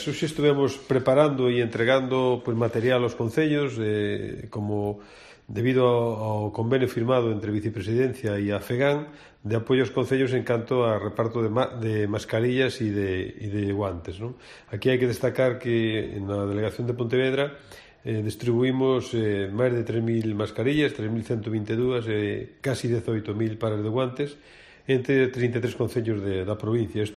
Declaraciones de José Manuel Cores Tourís, delegado territorial de la Xunta en Pontevedra